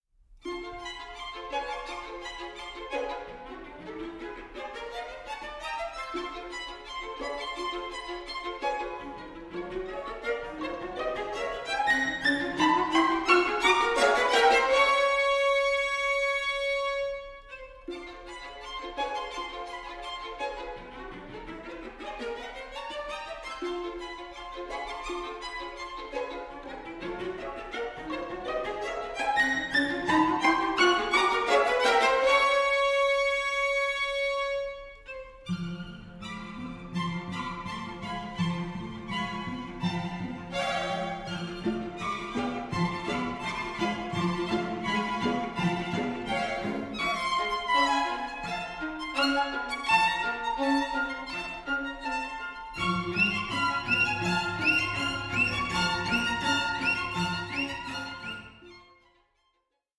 ENERGETIC AND ADVENTUROUS
string ensemble